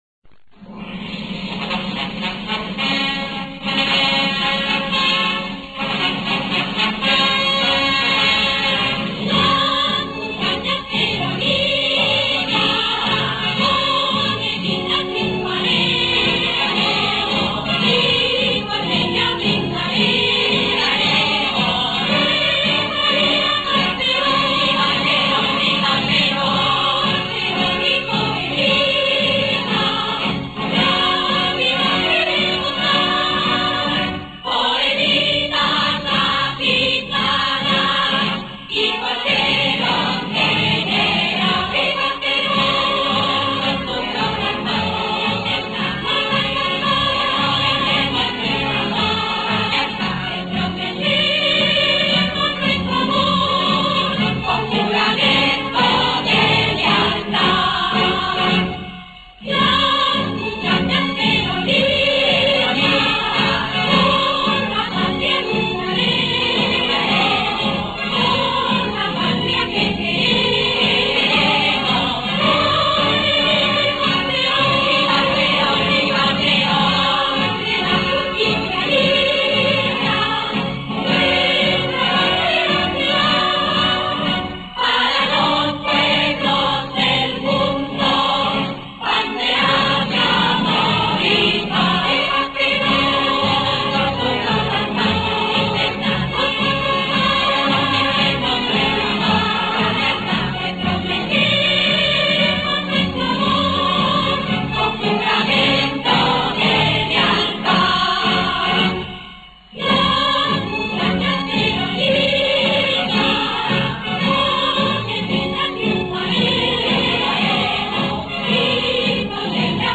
marcha argentina